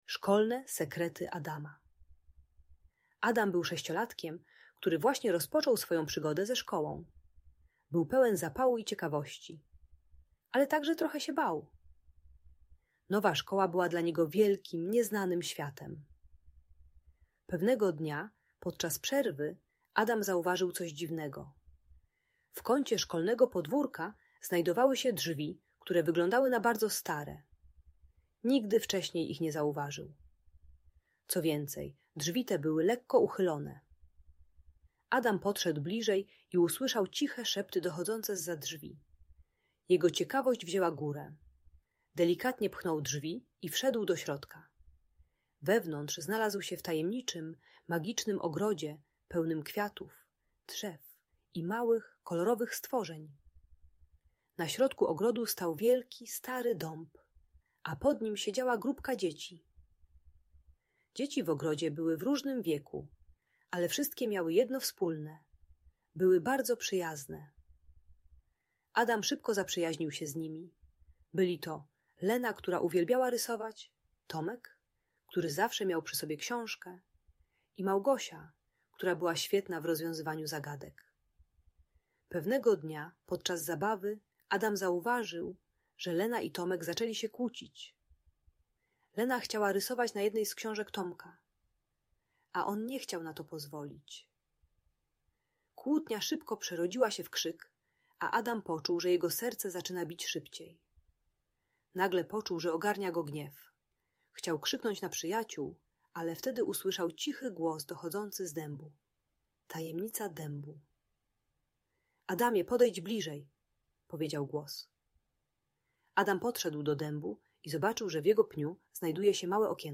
Szkolne Sekrety Adama - Audiobajka